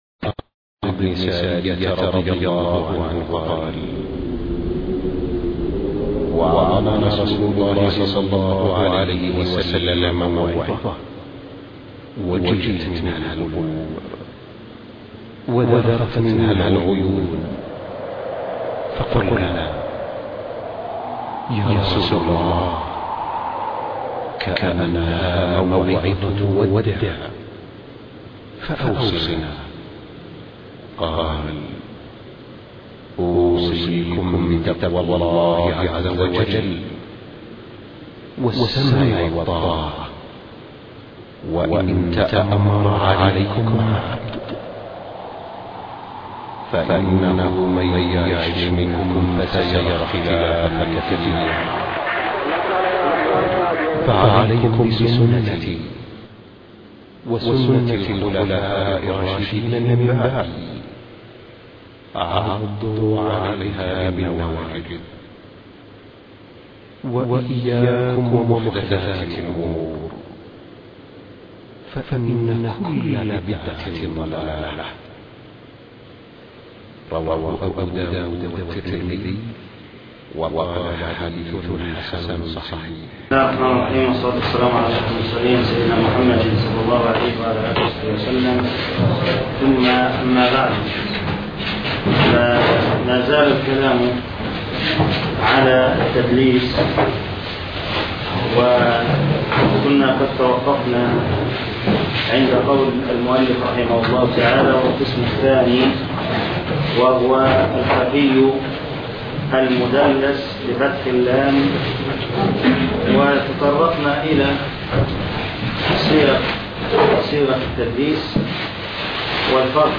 الدرس 25 ( شرح كتاب نزهة النظر )